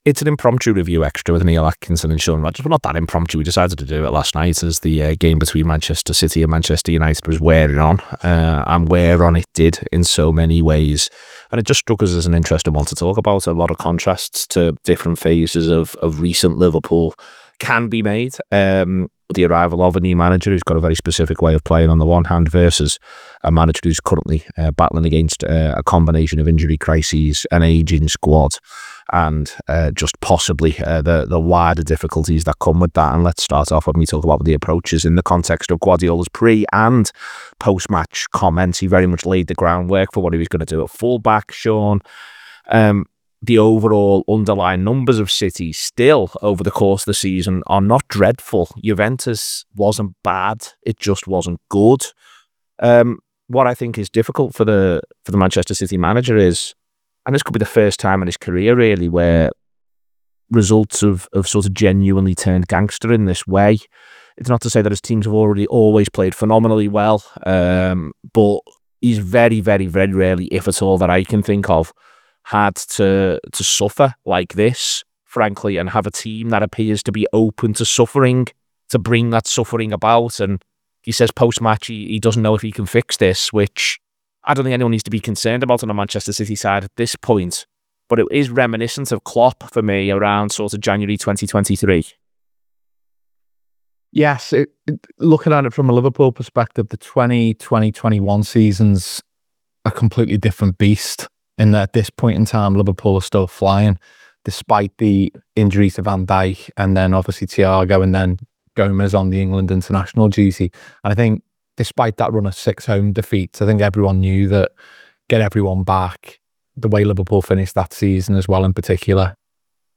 Below is a clip from the show – subscribe for more review chat around Manchester City 1 Manchester United 2…